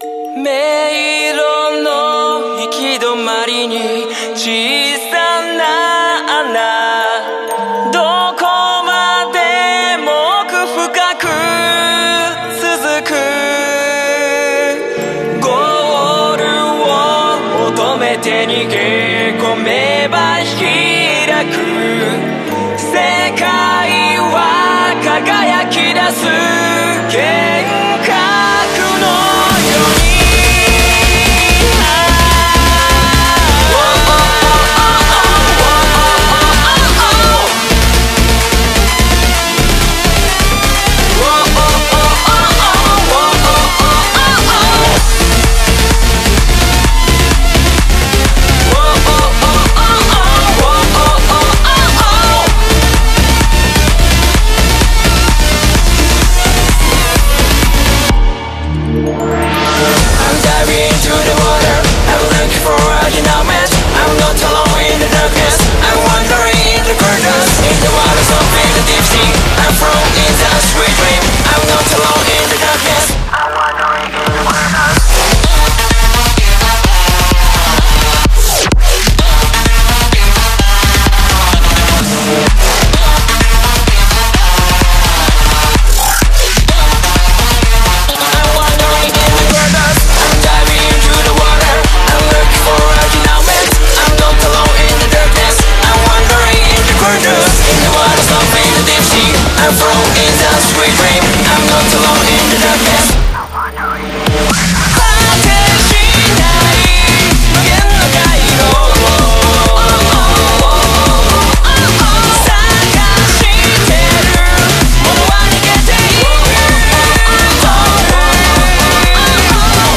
BPM64-128
Audio QualityCut From Video